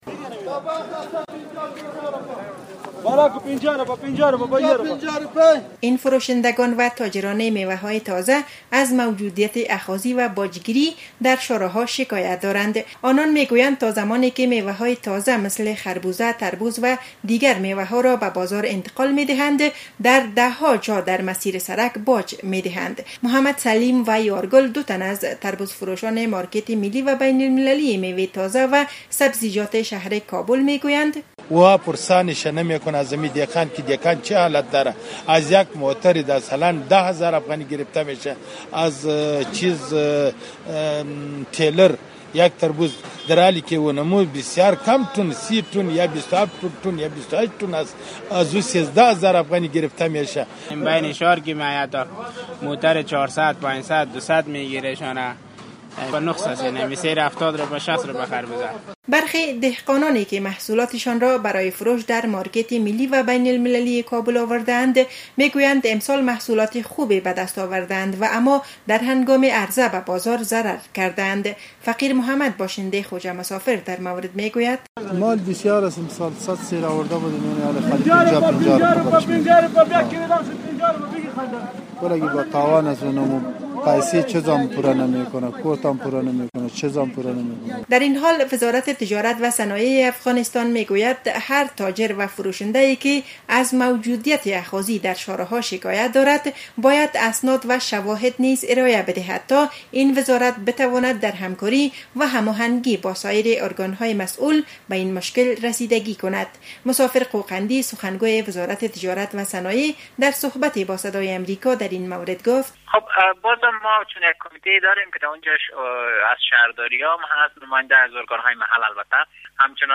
گزارش ویژۀ رادیویی را در این مورد از پیوند زیر بشنوید: